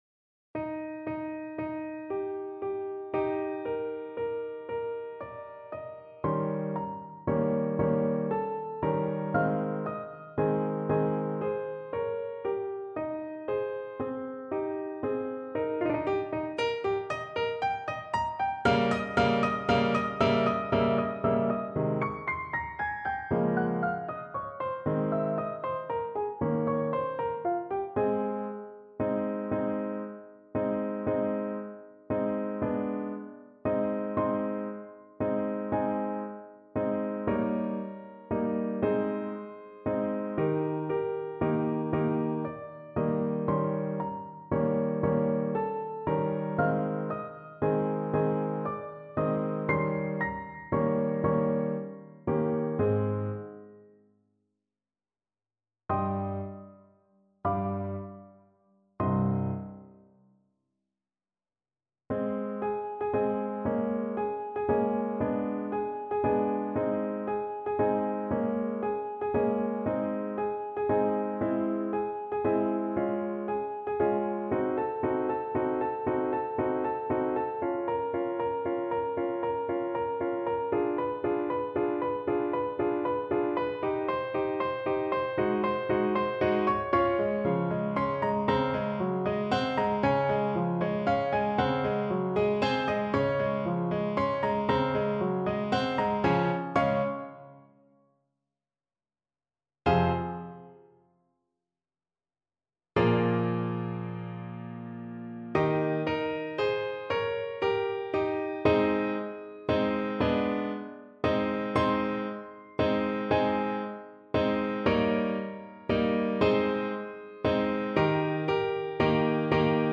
Free Sheet music for Trombone
Trombone
Ab major (Sounding Pitch) (View more Ab major Music for Trombone )
6/8 (View more 6/8 Music)
Andantino (=116) (View more music marked Andantino)
Bb3-F5
Classical (View more Classical Trombone Music)